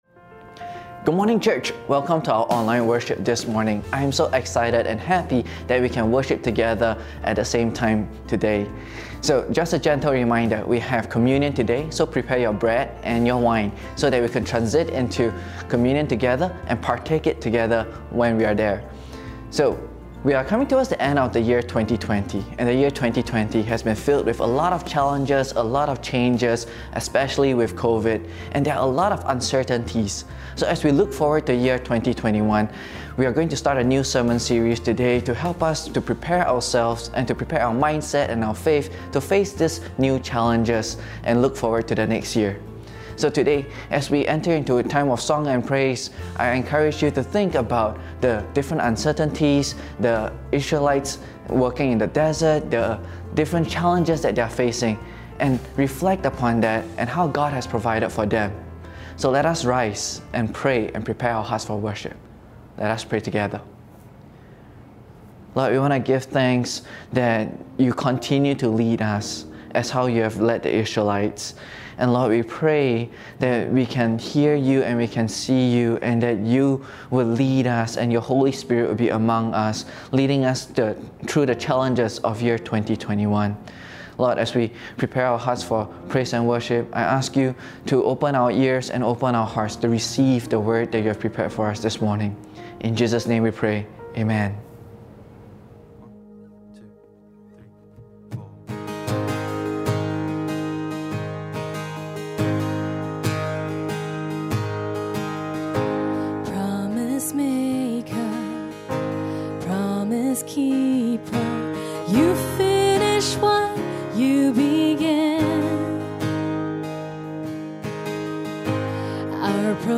Sermons | Koinonia Evangelical Church (English - NEW duplicate)